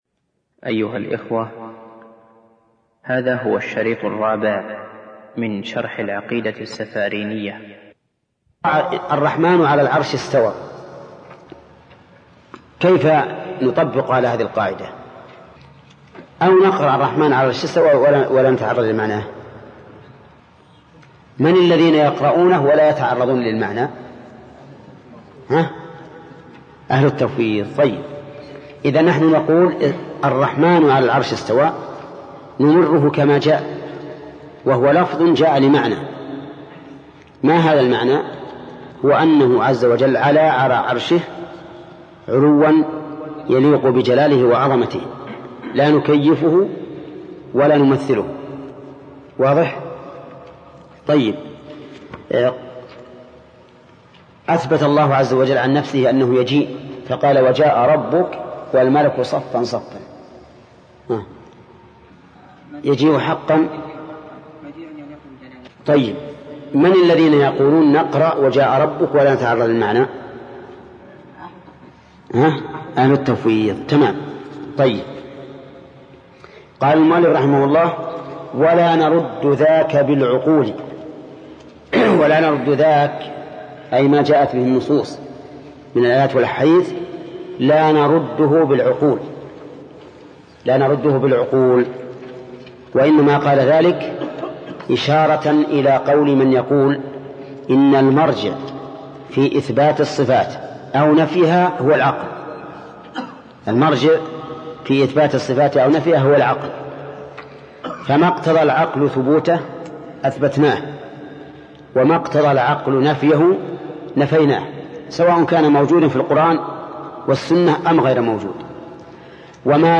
الدرس الرابع - فضيلة الشيخ محمد بن صالح العثيمين رحمه الله